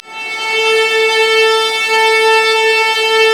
Index of /90_sSampleCDs/Roland L-CD702/VOL-1/STR_Vlas Bow FX/STR_Vas Sul Pont